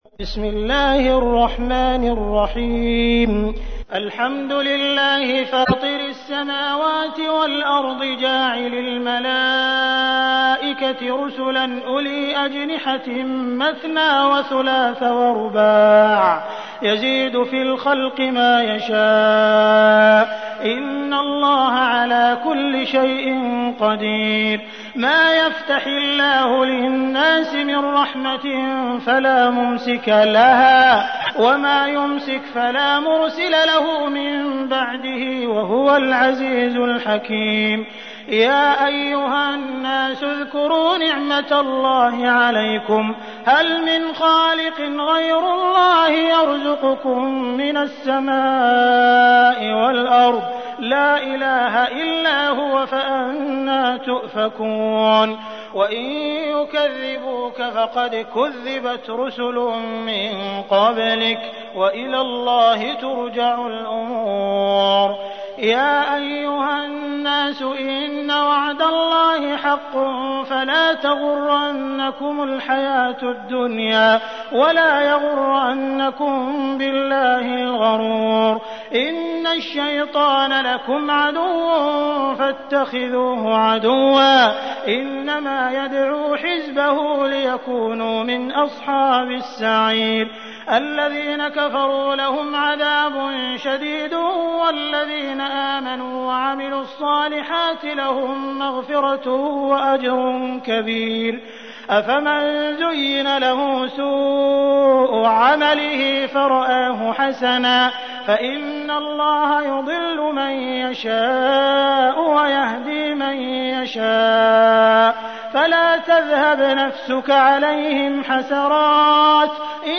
تحميل : 35. سورة فاطر / القارئ عبد الرحمن السديس / القرآن الكريم / موقع يا حسين